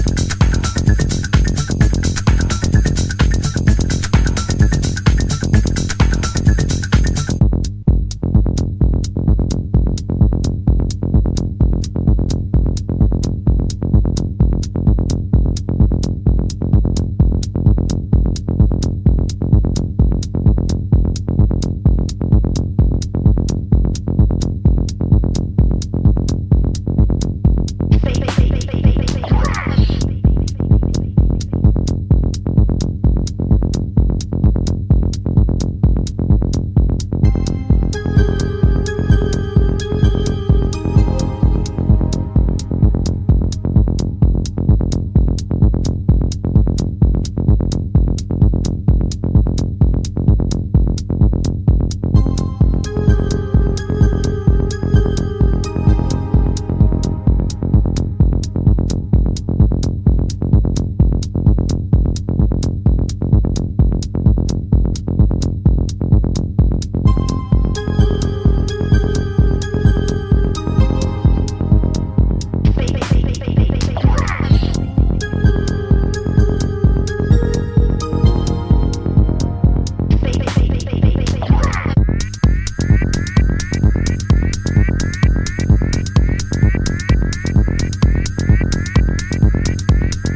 Acidic techno tracks